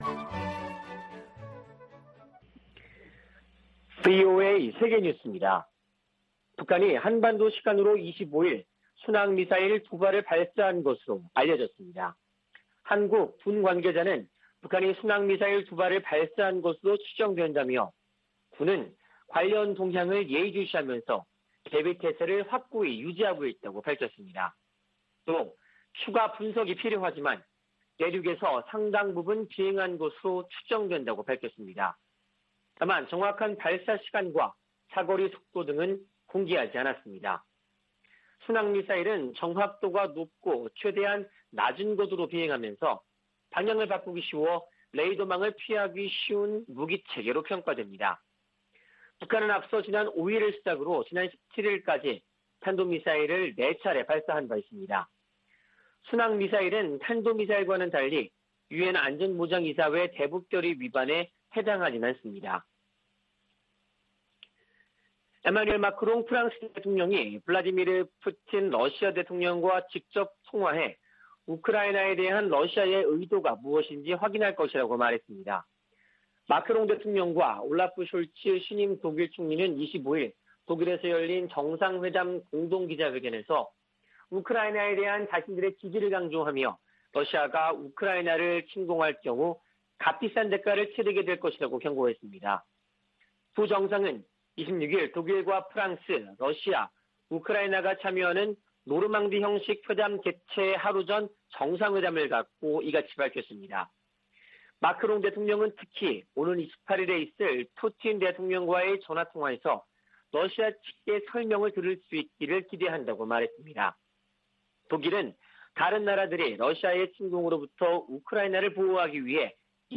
VOA 한국어 아침 뉴스 프로그램 '워싱턴 뉴스 광장' 2021년 1월 26일 방송입니다. 북한이 순항미사일로 추정되는 발사체 2발을 쏜 것으로 전해졌습니다.